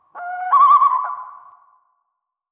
dash-move.wav